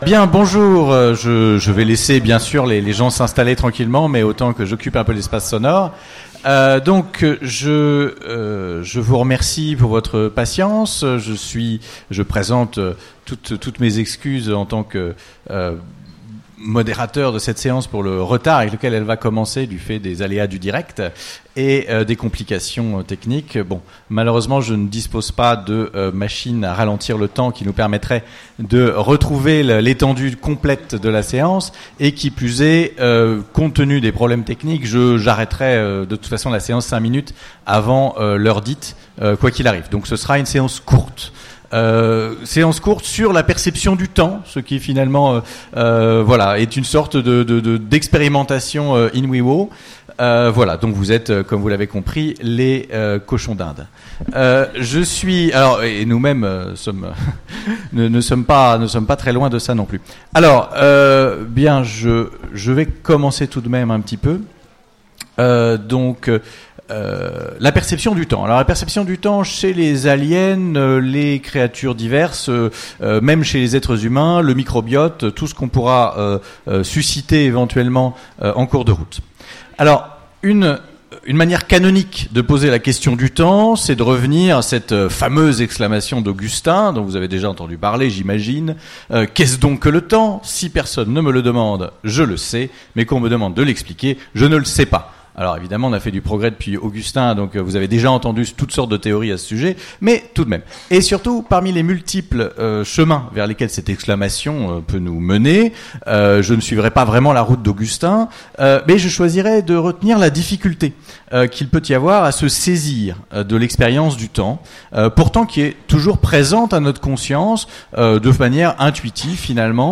Utopiales 2017 : Conférence Perception du temps chez les animaux, les humains et les aliens